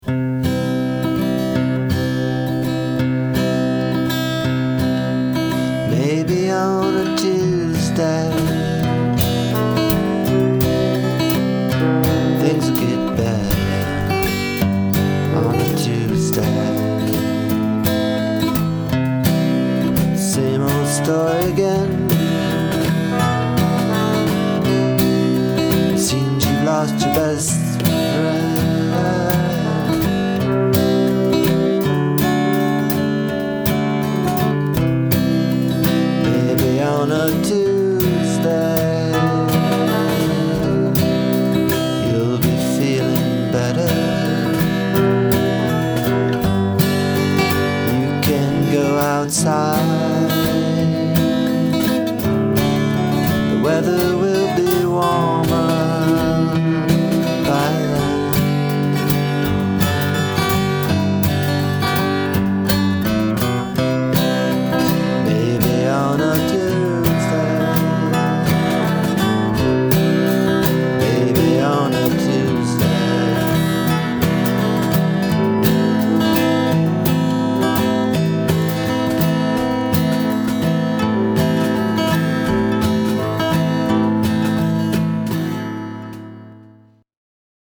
Tags: music folk pop original songs